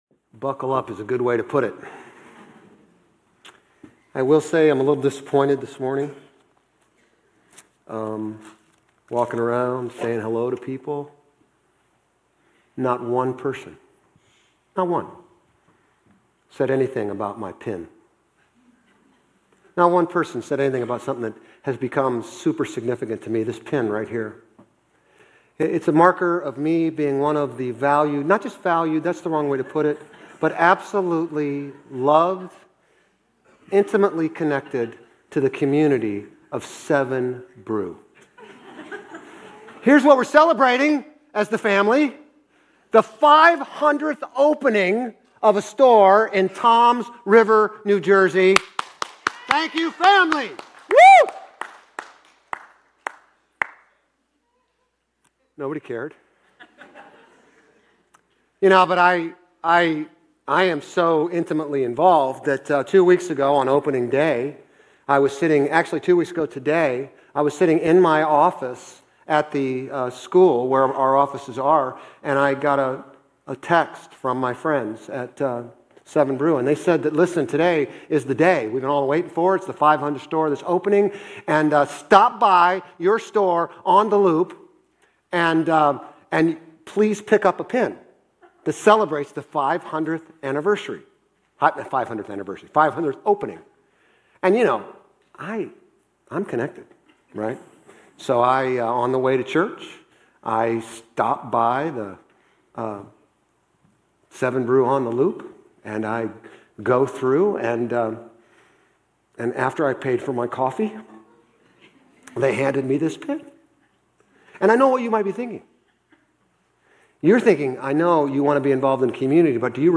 Grace Community Church Old Jacksonville Campus Sermons 10_26 Old Jacksonville Campus Oct 27 2025 | 00:37:30 1x 00:00 / 00:37:30 Subscribe Share RSS Feed Share Link Embed